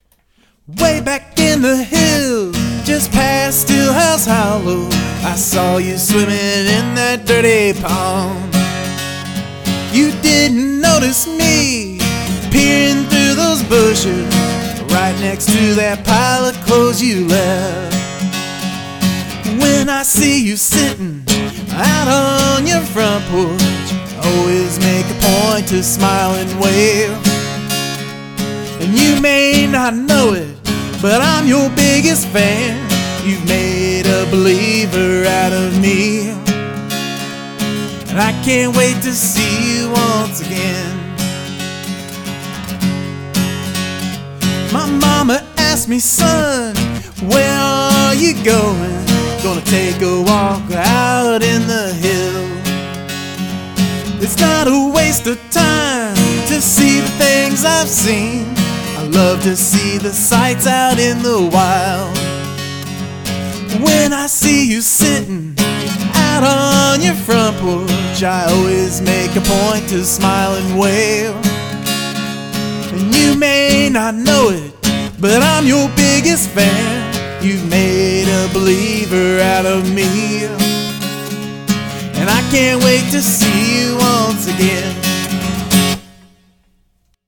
Short demo included.
I can hear some subtle harmonies topping it off very nicely.
Nice, I hear a mandolin in this one.